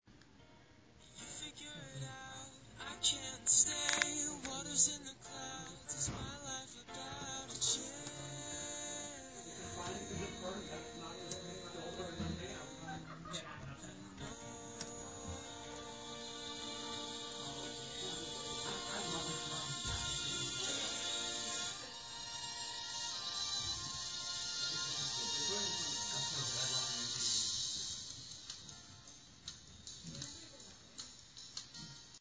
Field Recording – Psybrary
Far Sounds: Voices in distant hallways